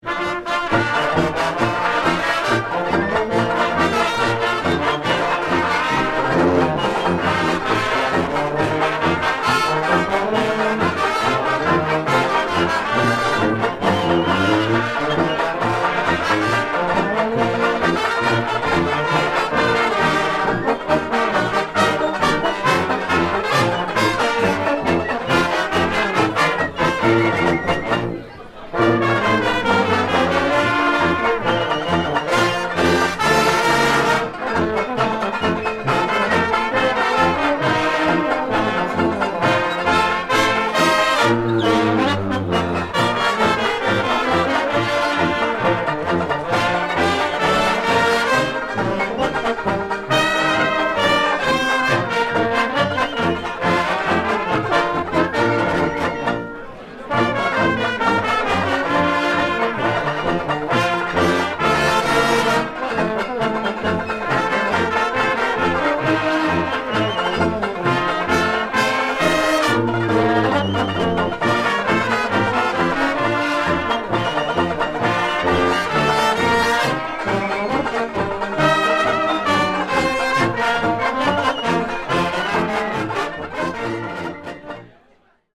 Marches et galops Aus der jugenzeit
interpreté par la Fanfare Octave Callot